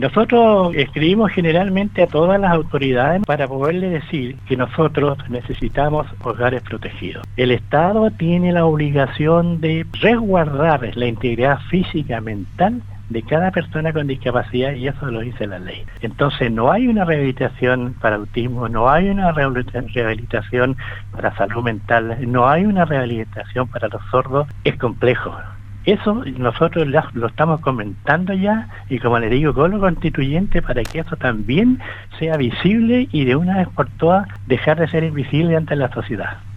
En entrevista con Radio Sago